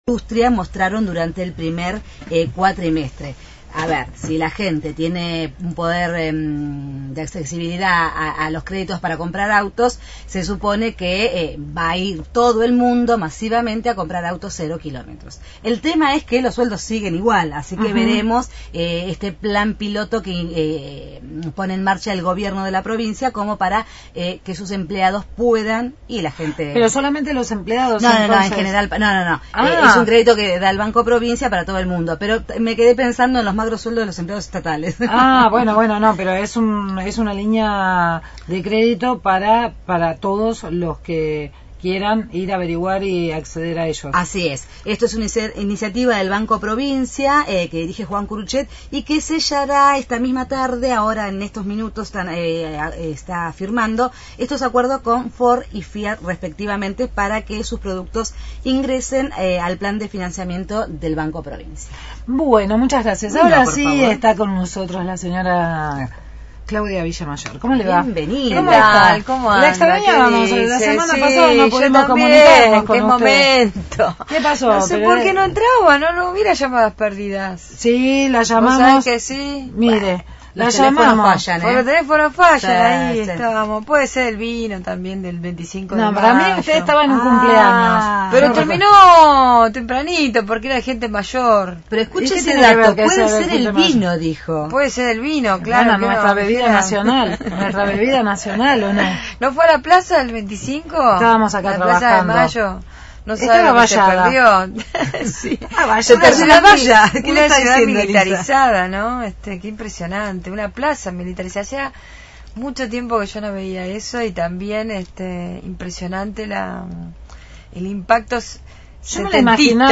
Entrevista Mara Brawer, Estela Díaz y Carolina Gaillard – Radio Universidad